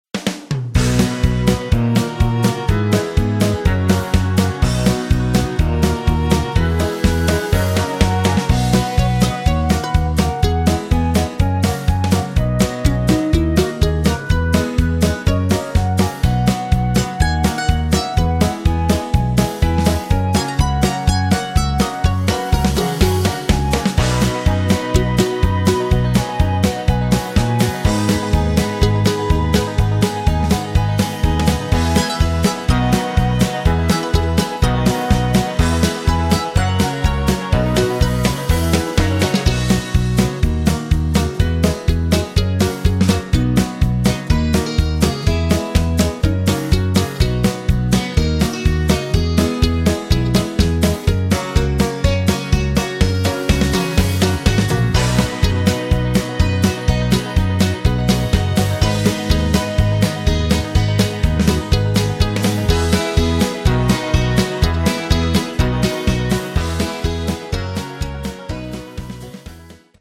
Square Dance Music
Music sample